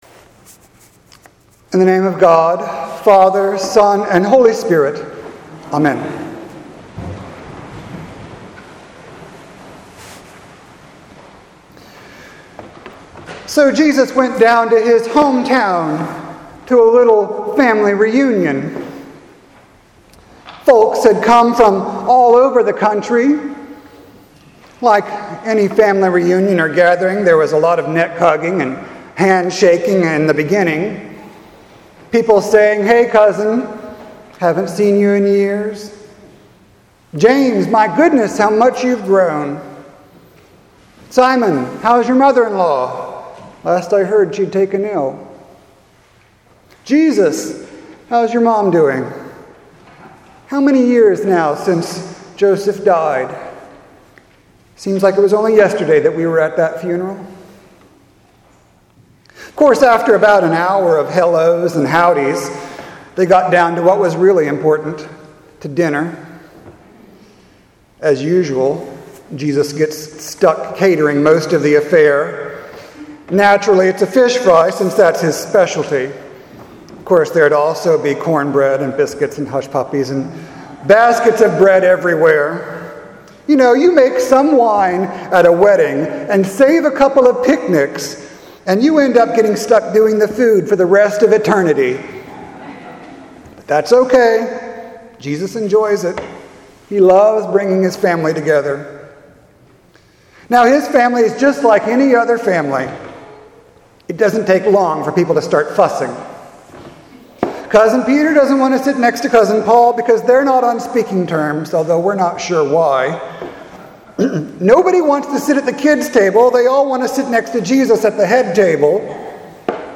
Sermon for Sunday, July 8th, 2018